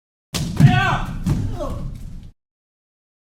Wrestling Dojo is a free sfx sound effect available for download in MP3 format.
yt_ecKfx7nu_Ic_wrestling_dojo.mp3